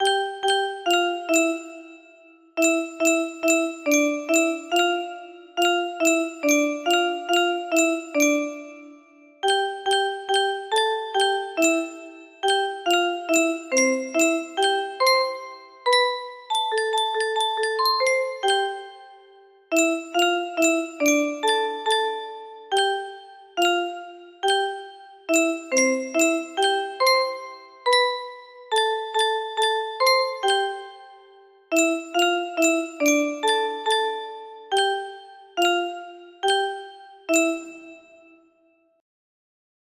Grand Illusions 30 music boxes More